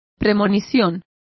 Complete with pronunciation of the translation of premonition.